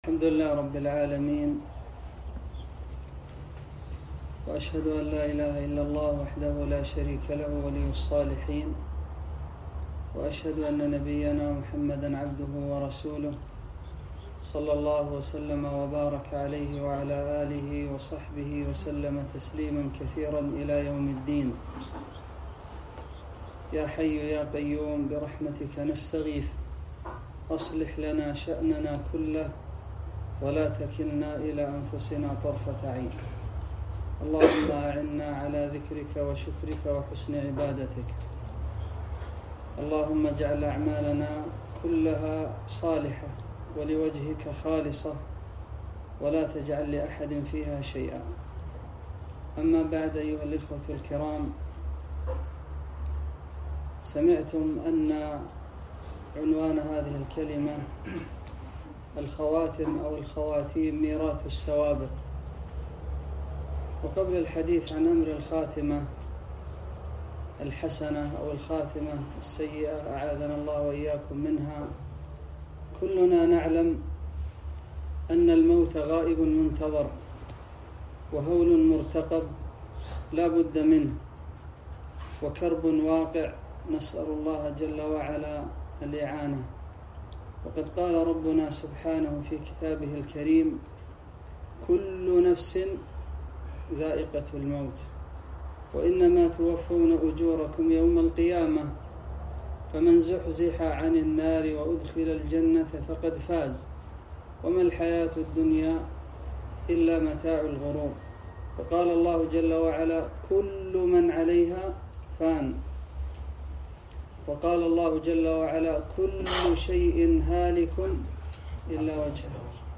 محاضرة - الخواتم ميراث السوابق